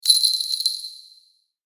鈴.mp3